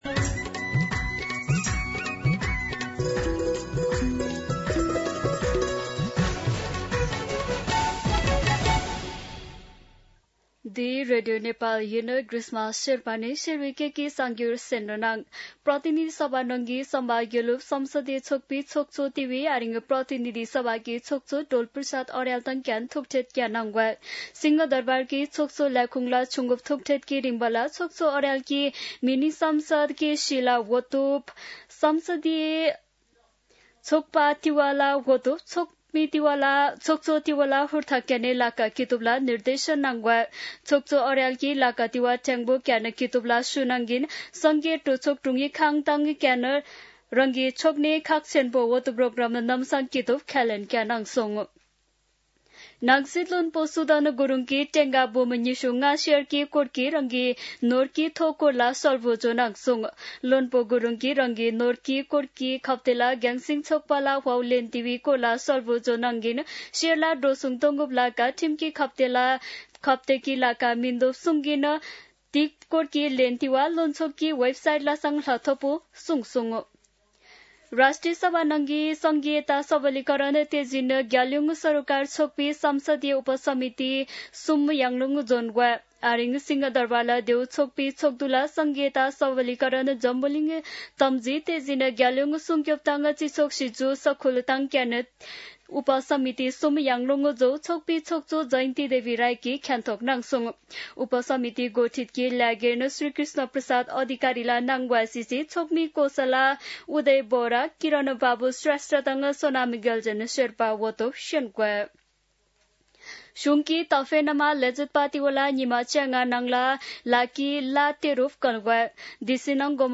शेर्पा भाषाको समाचार : ७ वैशाख , २०८३
Sherpa-News-07.mp3